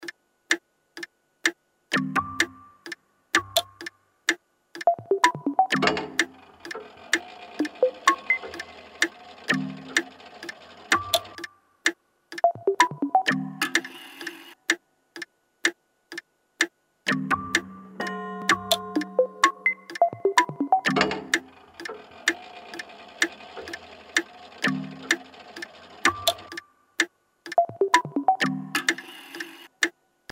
Intromusik